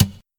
hit_1.ogg